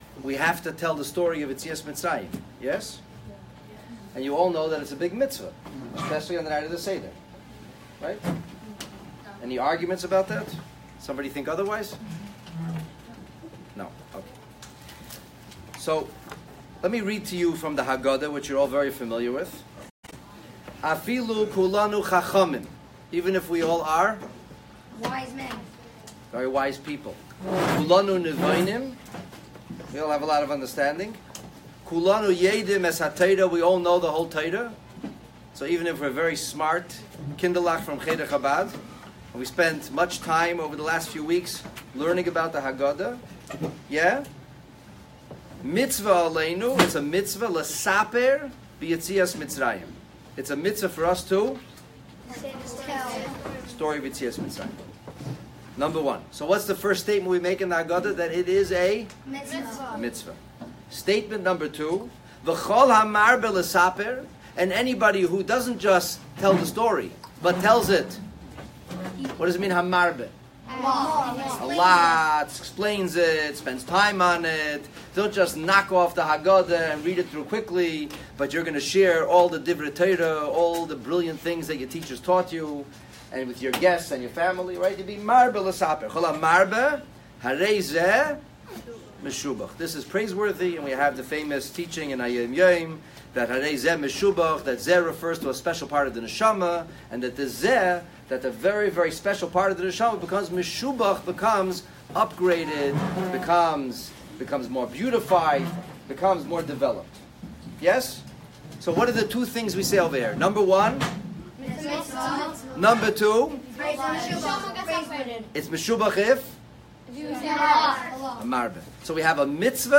A talk to the children of The Shmuel Zehavi Cheder Chabad!